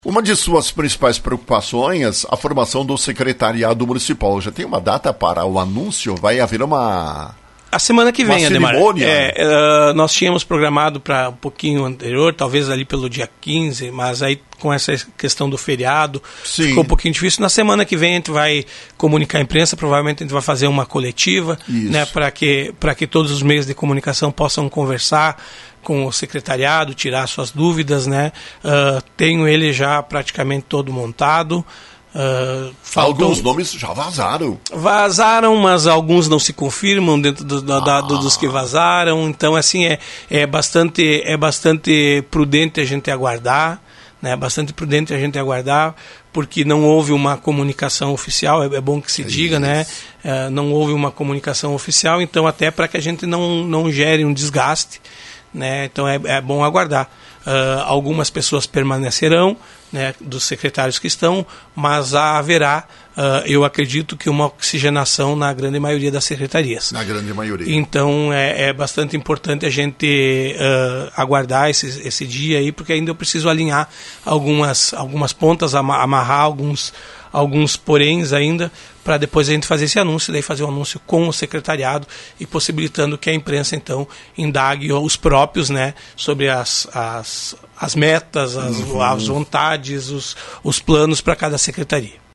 Futuro prefeito de Lagoa Vermelha, Eloir Morona, foi abordado, pela Rádio Lagoa FM, sobre a montagem de seu secretariado. Disse que está, praticamente, definido. O anúncio ocorrerá em coletiva com a imprensa quando todos os secretários estarão presentes.